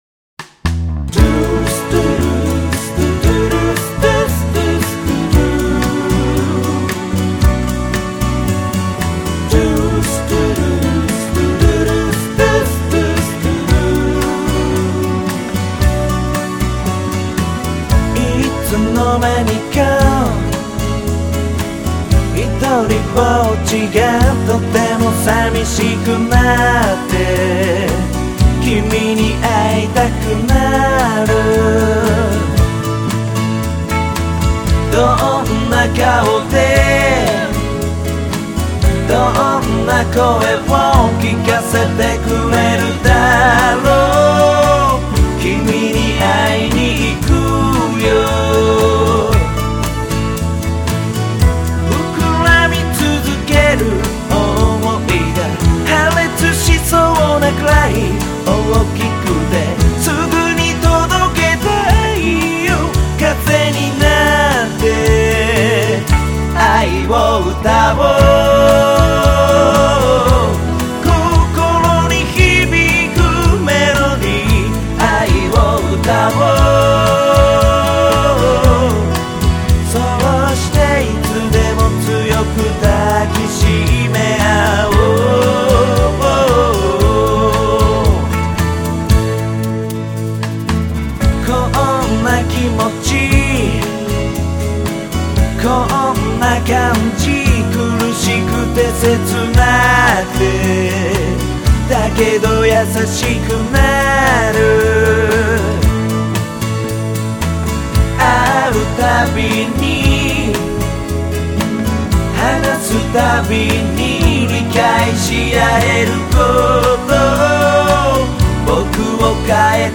サッカーをする姿にとてもよく似合っていた挿入歌。